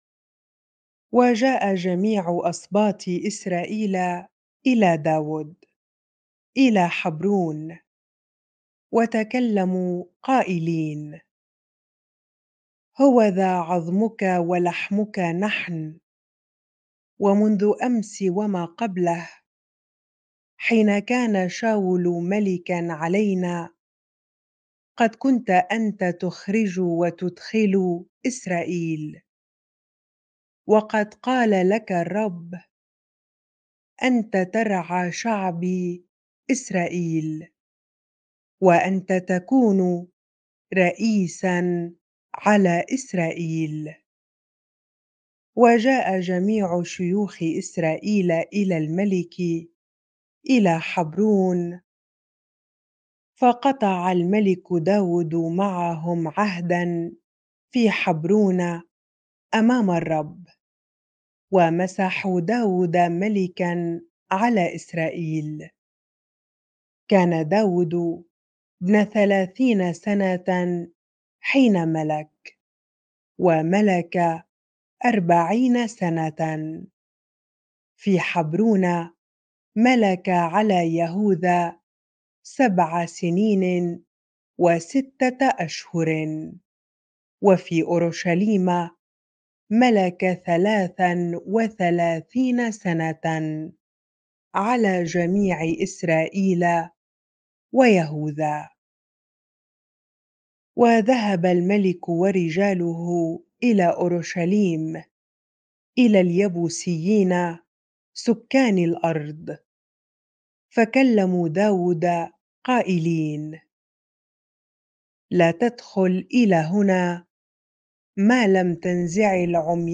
bible-reading-2Samuel 5 ar